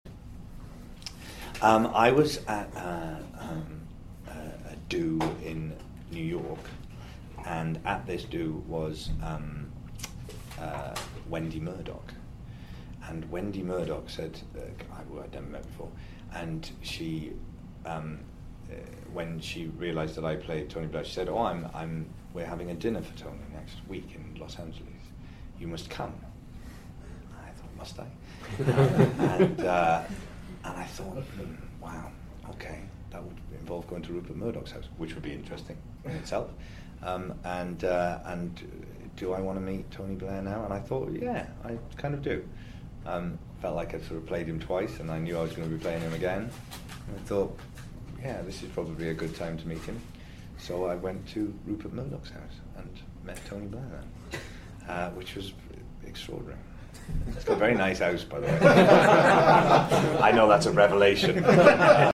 I’ve posted three short audio files below from the round table interview with Michael last month at a London hotel.